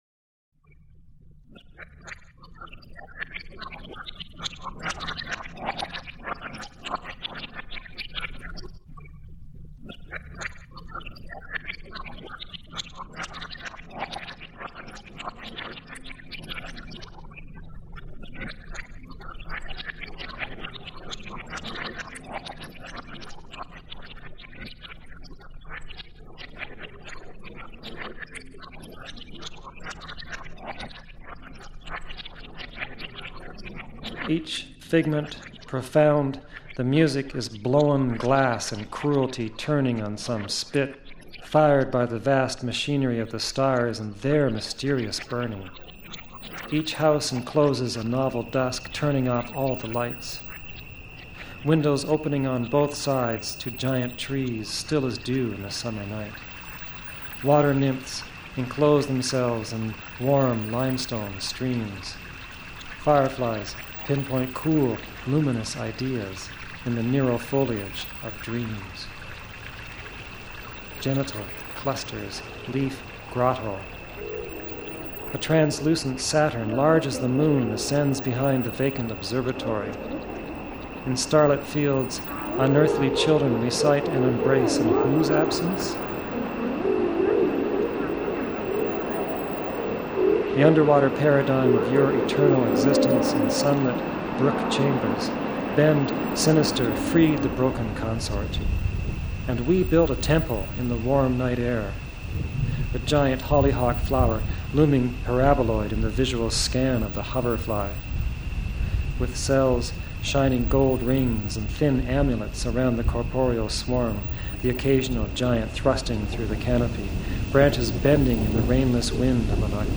Text & reading by Christopher Dewdney
A Natural History of Southwestern Ontario is author Christopher Dewdney's fascinating poetic exploration of the landscape, creatures and atmospheric phenomena of Canada's southernmost region. Published in instalments over the past three decades, this work can now be heard in its entirety, spoken by the author and vividly orchestrated with the sounds of the terrain it celebrates.
Dewdney's masterpiece is taken to a new experiential realm in one of the most ambitious soundscapes ever created—a hyperlucid, sometimes hallucinatory domain of time winds, spring trances and Cenozoic asylums.